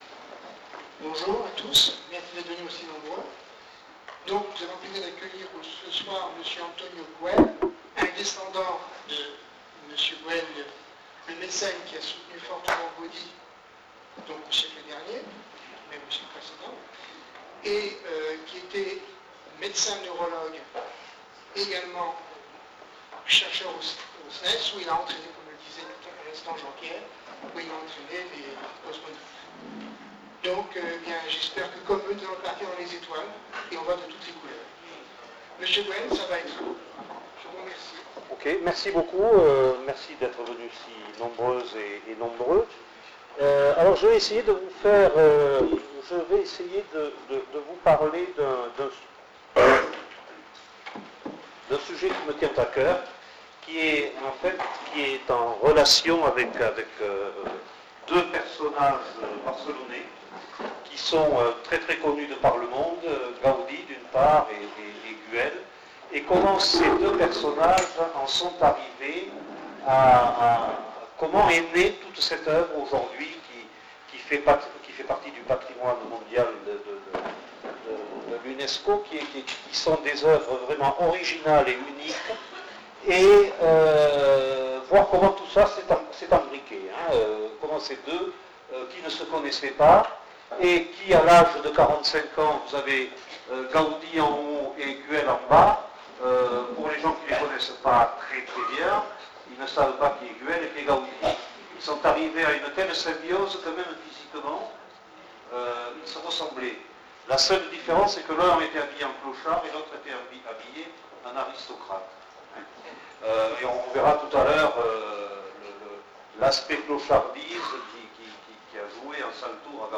Montesquieu-Volvestre - Salle polyvalente Ecouter la conférence https